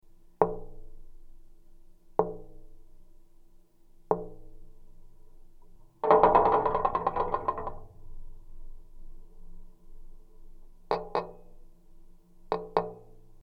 Geophone is an omnidirectional contact microphone.
Metal fence
Metal-fence.mp3